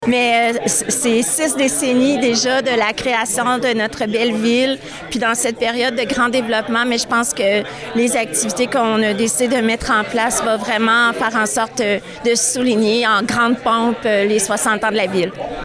En conférence de presse vendredi, la mairesse, Lucie Allard, était heureuse de souligner les 60 bougies de la ville.